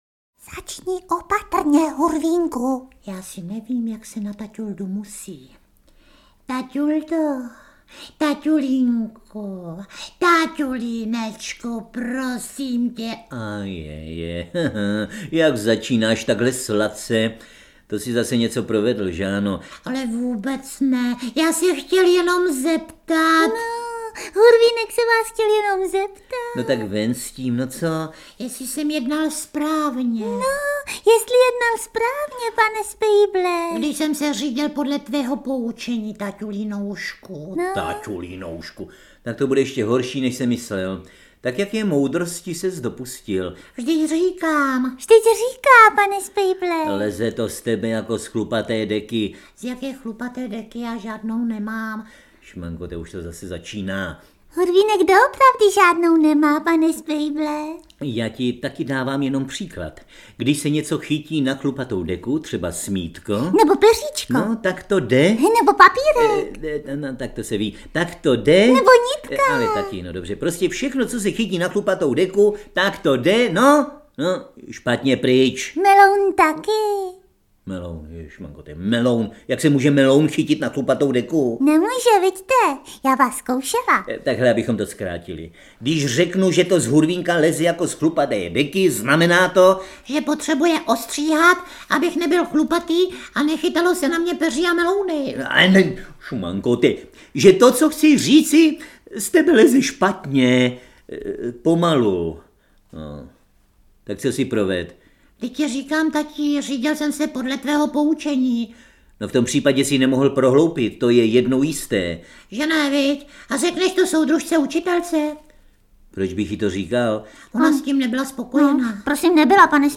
Interpreti:  Miloš Kirschner, Helena Štáchová
Několik neznámých Hurvínkových příběhů z rozhlasového archivu (ze sedmdesátých a osmdesátých let), které spojuje autorství Vladimíra Straky, Luboše Homoly, Jiřího Středy a interpretační umění Miloše Kirschnera a Heleny Štáchové.